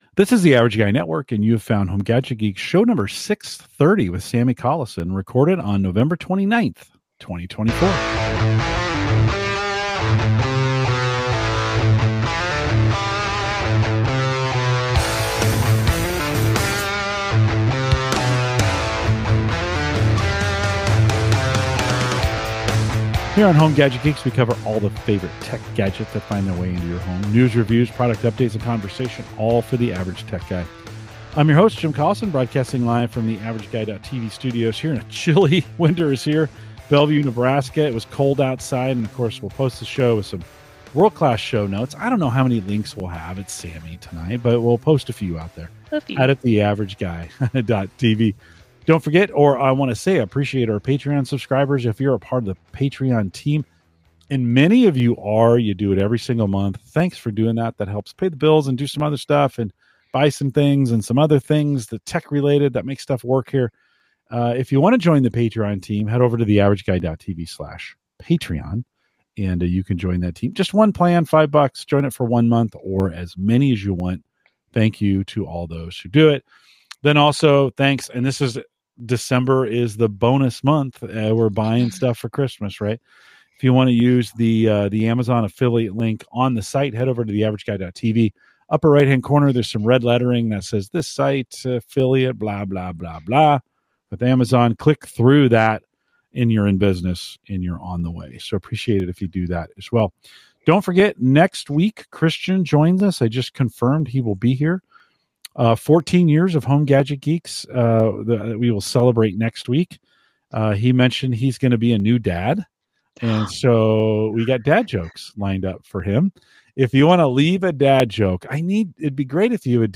We delve into online job hunting, using tools like Indeed and ChatGPT for resume building, and explore the blend of Mac and PC technology in schools. Join us for a conversation about growth, career development, and the evolvi